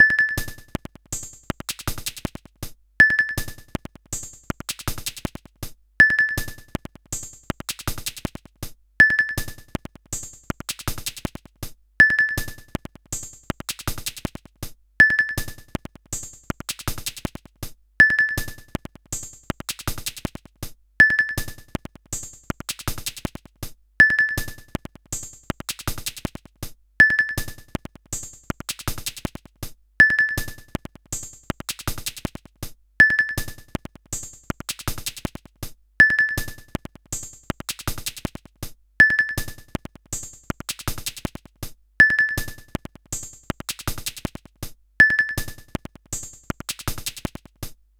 Bucle de percusión electrónica
Música electrónica
percusión
repetitivo
rítmico
sintetizador